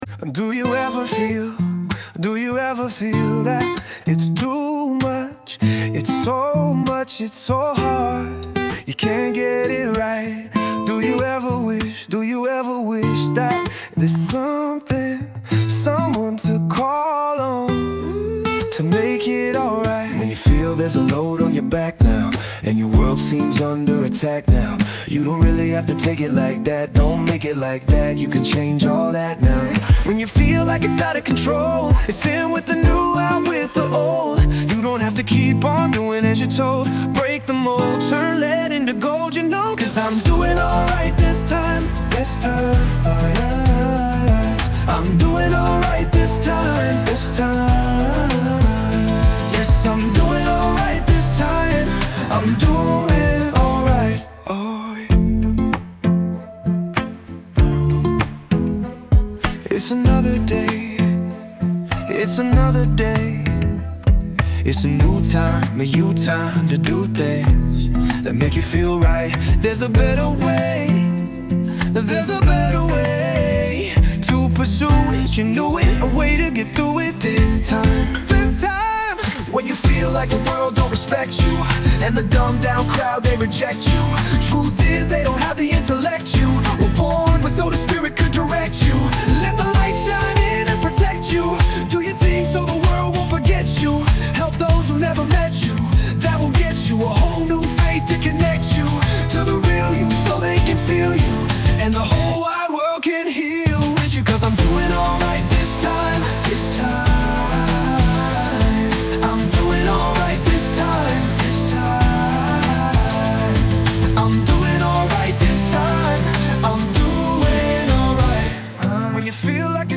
Musical Poetry
Most vocals are AI generated.
Tagged Under Easy Listening Music Videos Musical Poetry Pop